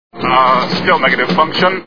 Dr Strangelove Movie Sound Bites